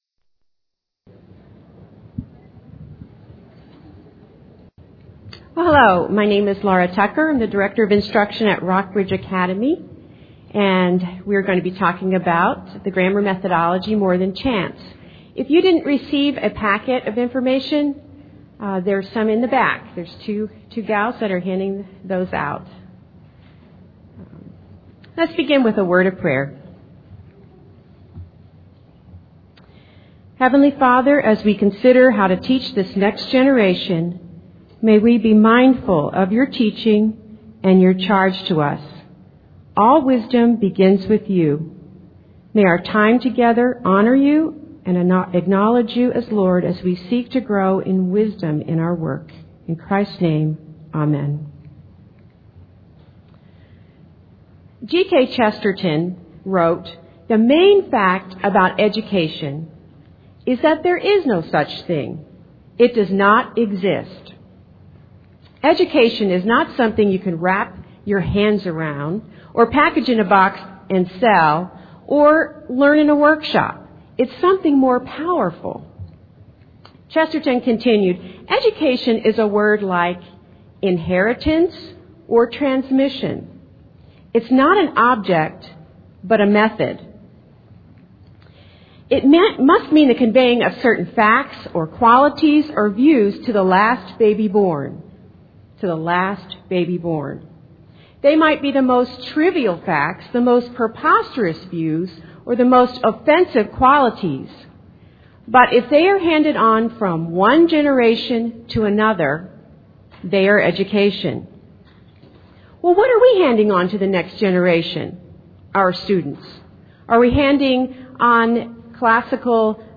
2004 Workshop Talk | 0:55:16 | K-6, General Classroom